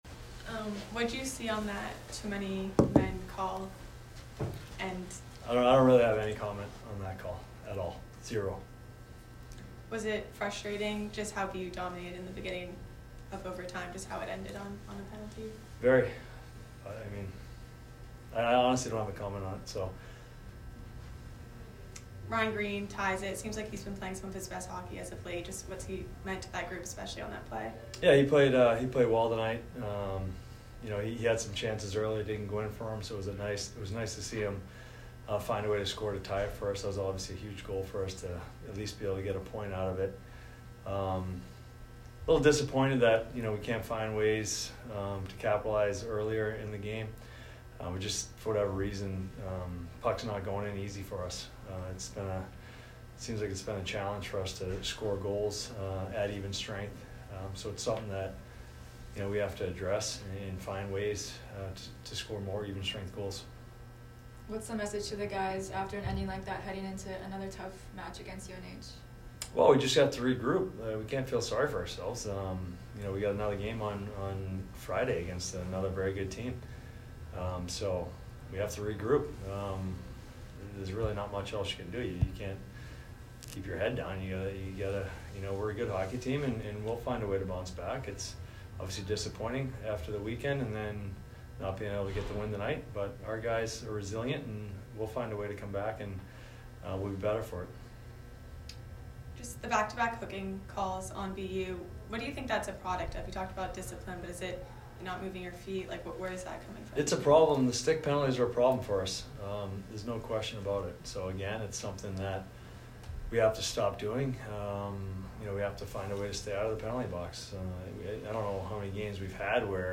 Men's Ice Hockey / Northeastern Postgame Interview